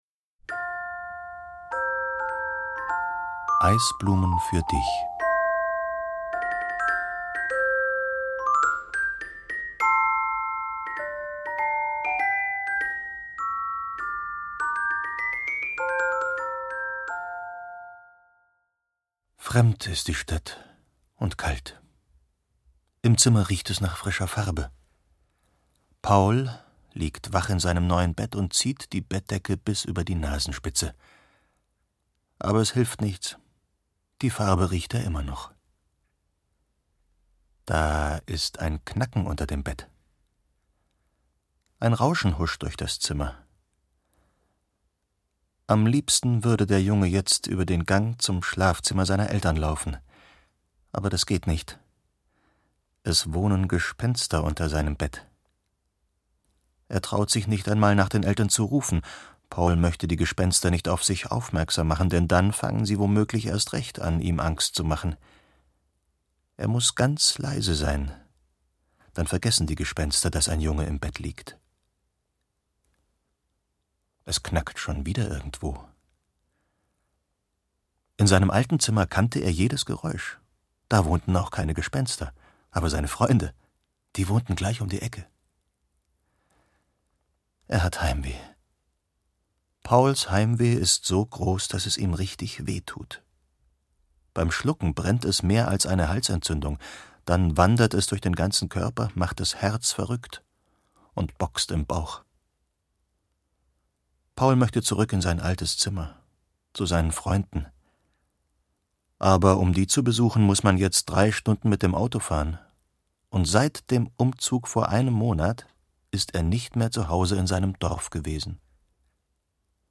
Eine poetische Geschichte von Abschieden, Veränderungen und wundersamen Überraschungen… Ulrich Noethen erzählt ein wunderschönes Wintermärchen von der Macht der Fantasie.
Klarinette
Klavier
Erzähler: Ulrich Noethen